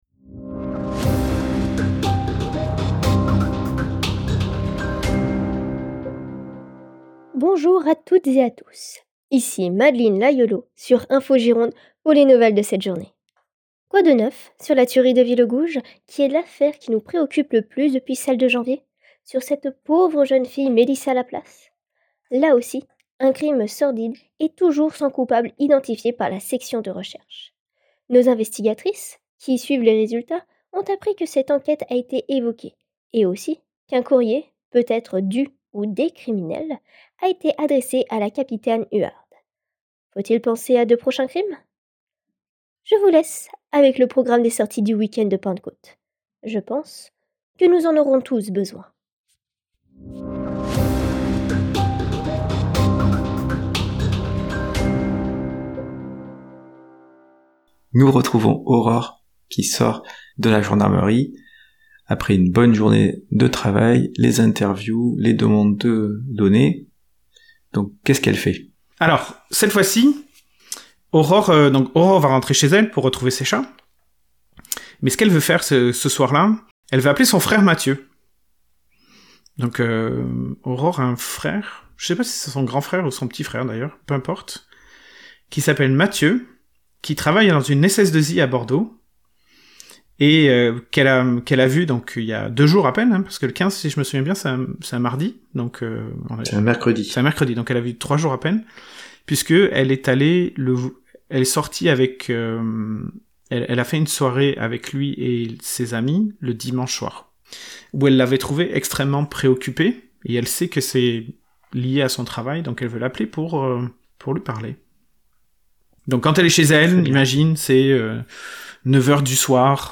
“Phone rings-(3x)”